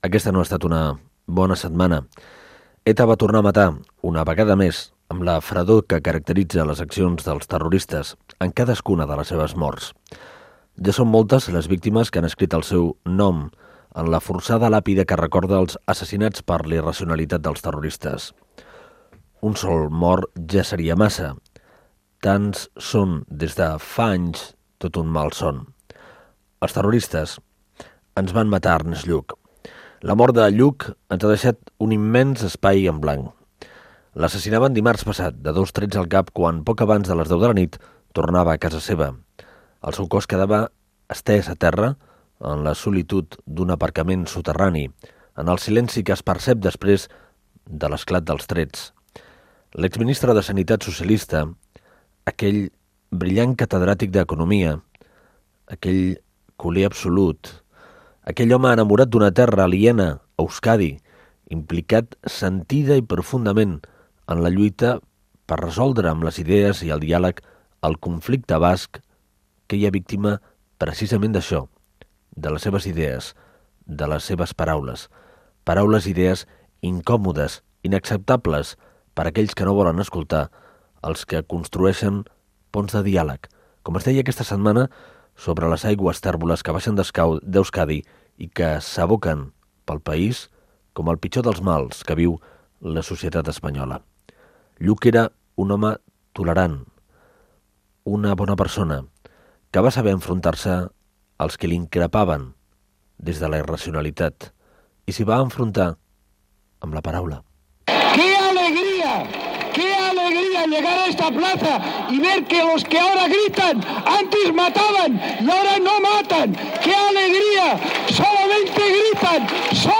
21be527622f42a1a0a64137db347dc016ec0143a.mp3 Títol COM Ràdio Emissora COM Ràdio Barcelona Cadena COM Ràdio Titularitat Pública nacional Nom programa Tal com som Descripció Especial emès poc dies després de l'assassinat del polític Ernest Lluch. Fragment d'una entevista realitzada l'1 d'agost de 2000.